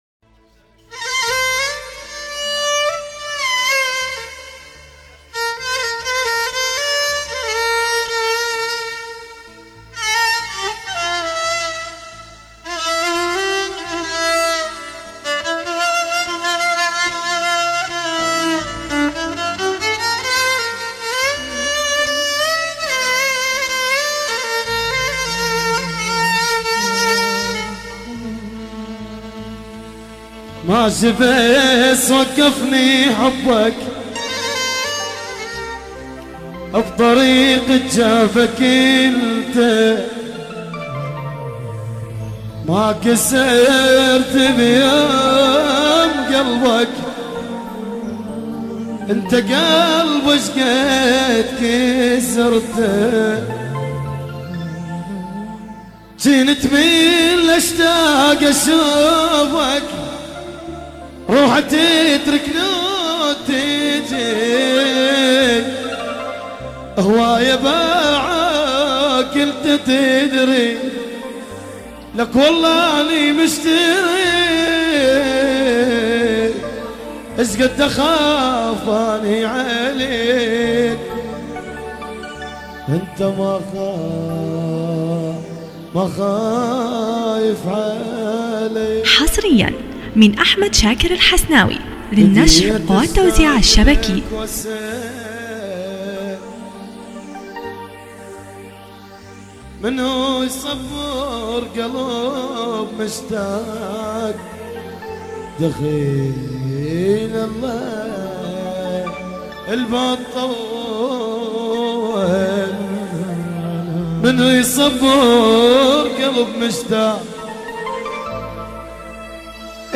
حفلة